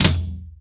TOM     4B.wav